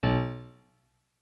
MIDI-Synthesizer/Project/Piano/15.ogg at 51c16a17ac42a0203ee77c8c68e83996ce3f6132